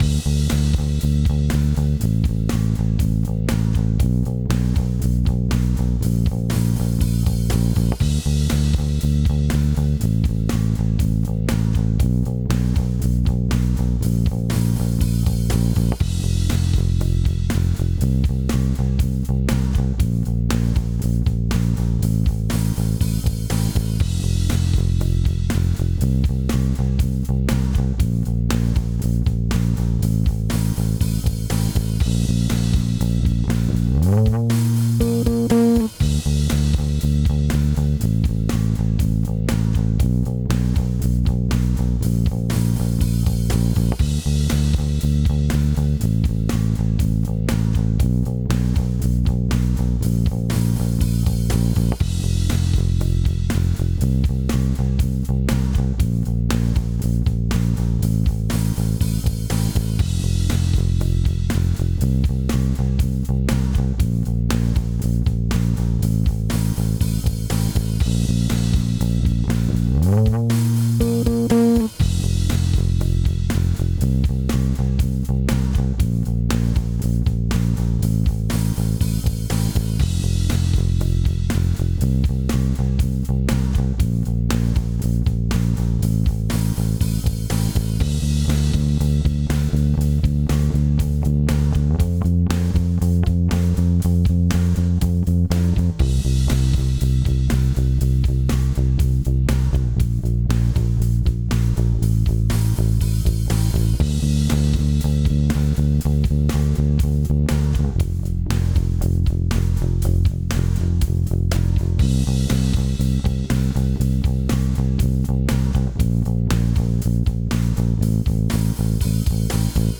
Dwa kawałeczki nagrywane na szybko na line6 u kumpla.